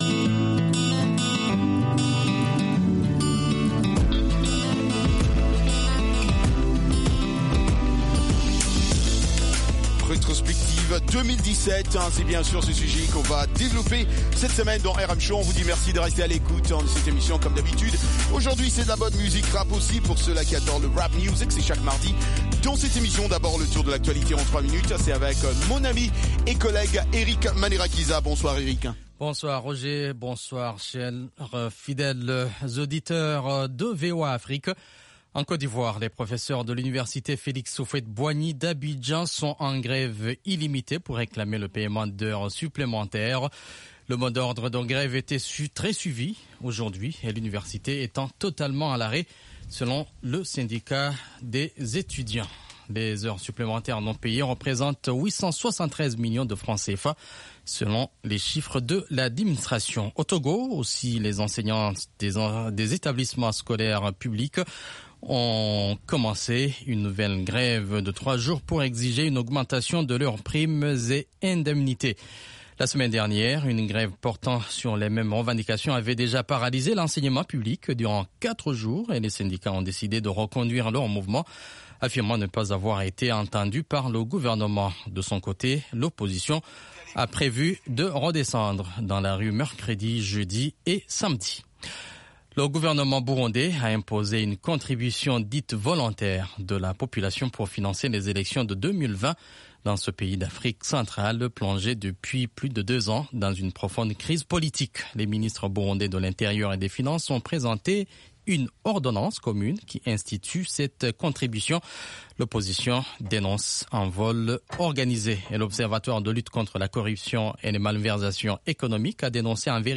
Cette émission est interactive par téléphone.